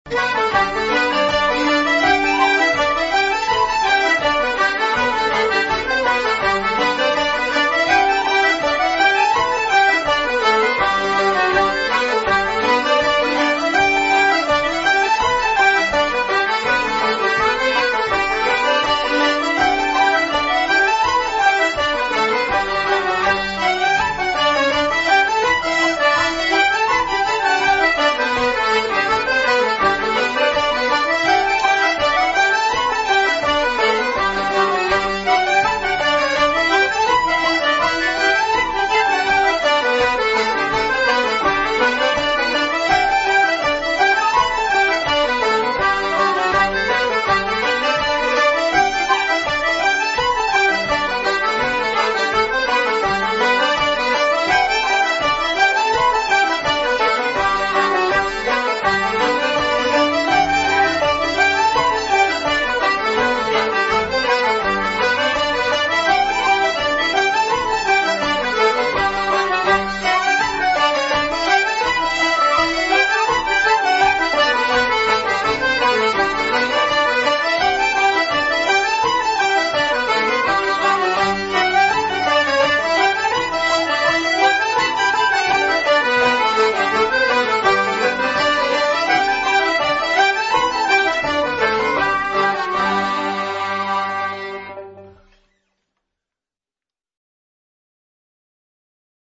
50 Cronin-s Hornpipe.mp3